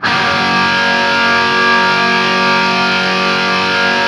TRIAD F# L-L.wav